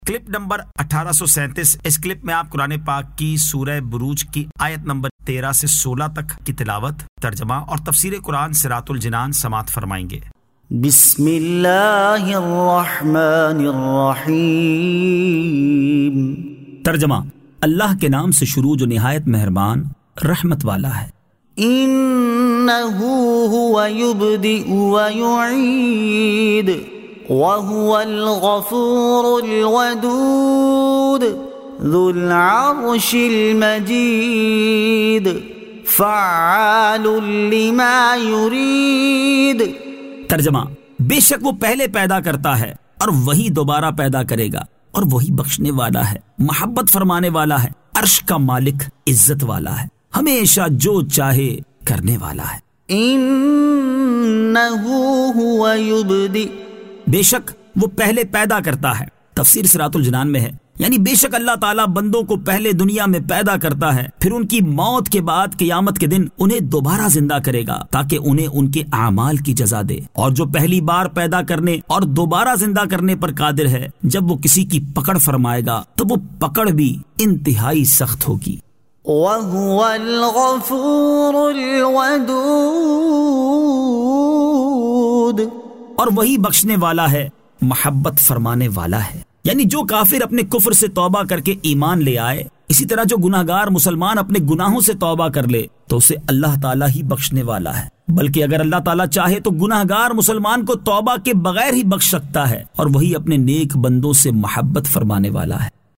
Surah Al-Burooj 13 To 16 Tilawat , Tarjama , Tafseer